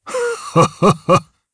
Dakaris-Vox_Happy1_jp.wav